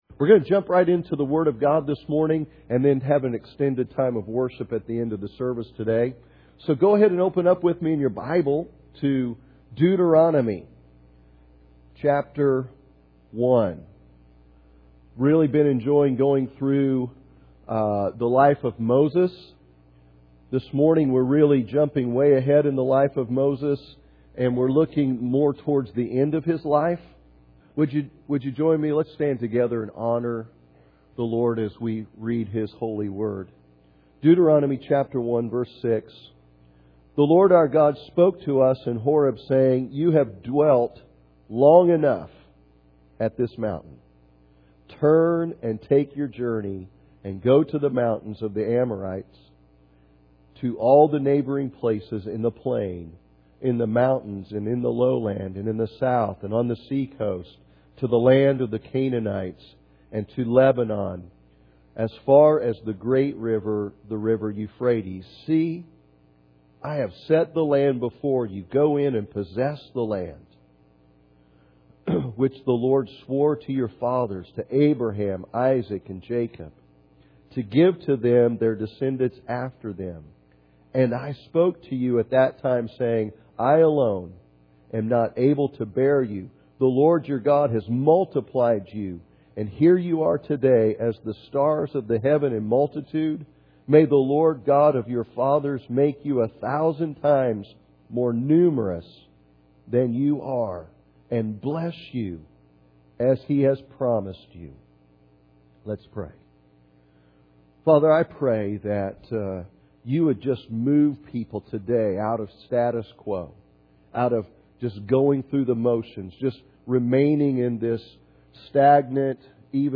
Sunday Morning Service
Audio Sermon